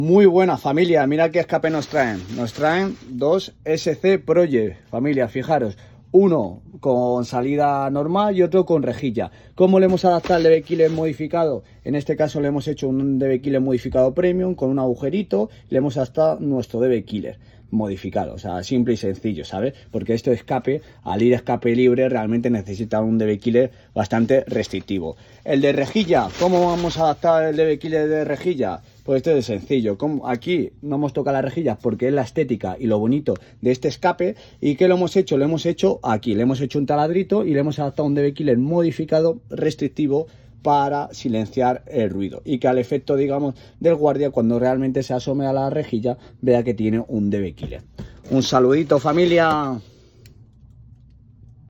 Nos traen dos escapes ScPROJECT versión Aliexpress para adaptarle dos de nuestros dbkiller modificados, así hacer que suenen como tiene que sonar familia